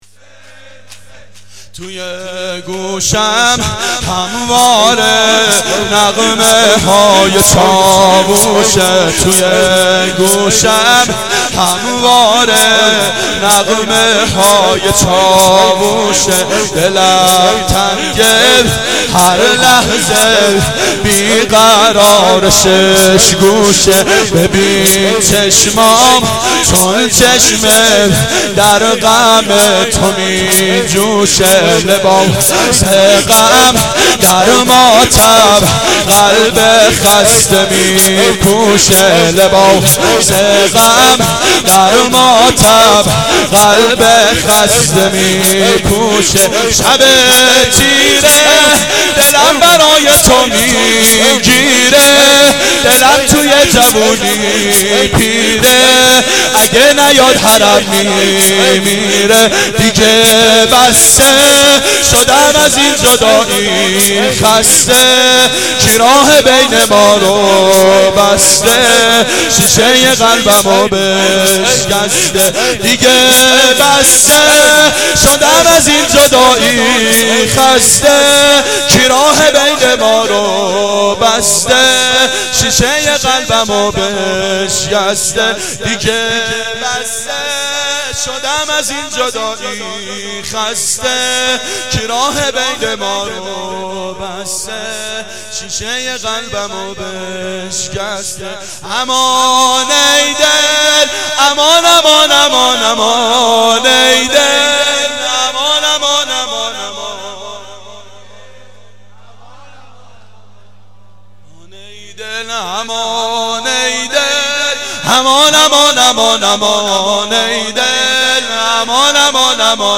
نغمه های چاووش
شب اول محرم 89 گلزار شهدای شهر اژیه
07-نغمه-های-چاووش.mp3